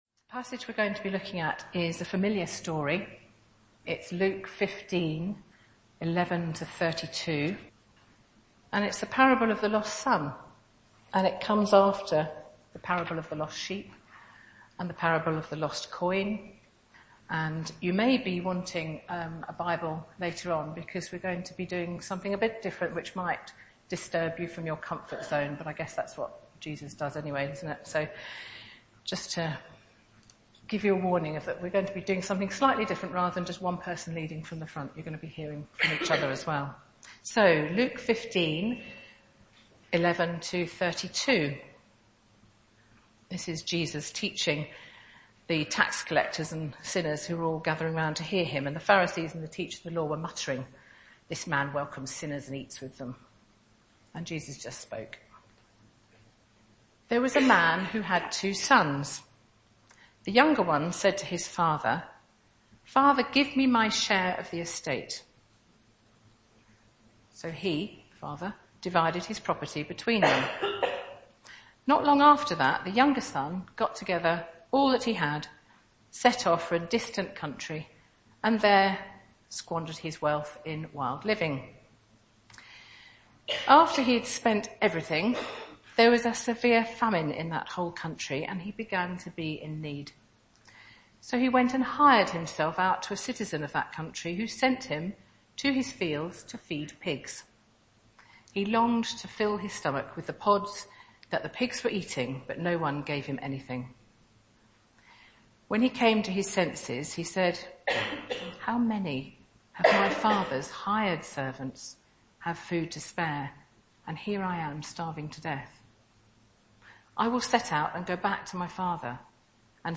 The Lost Son – Woodside Baptist Church